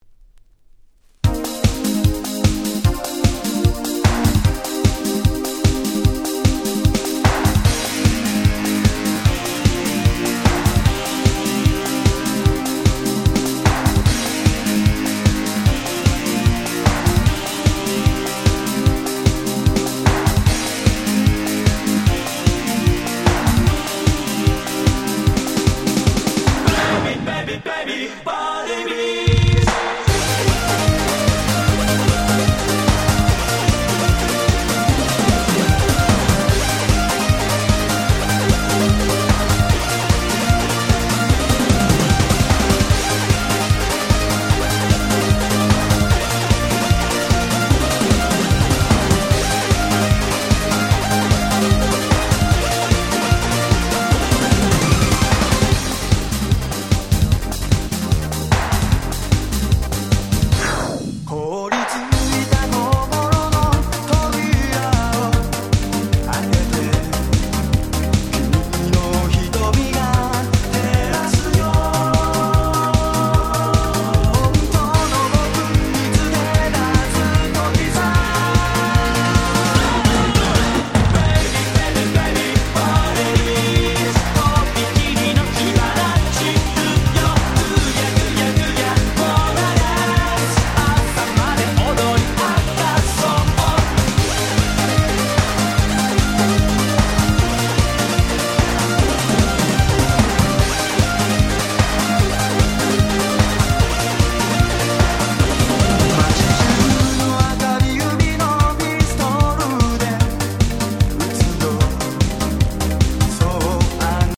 95' Smash Hit J-Pop / Super Euro Beat !!